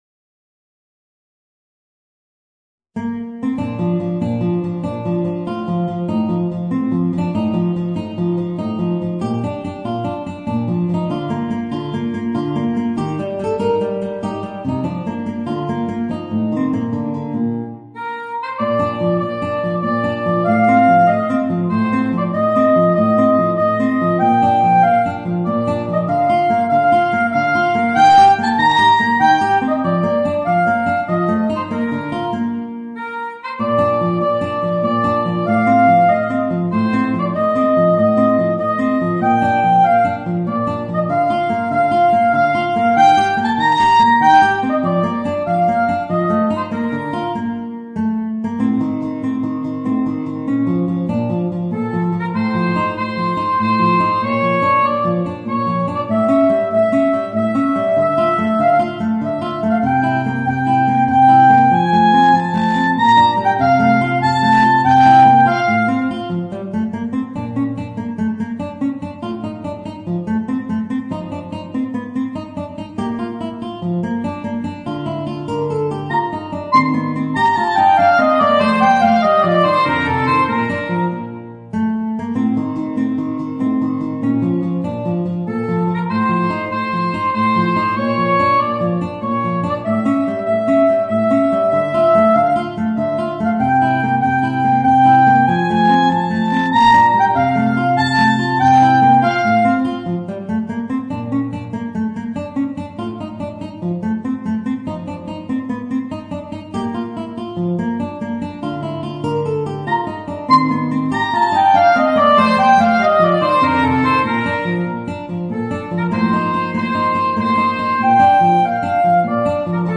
Voicing: Soprano Saxophone and Guitar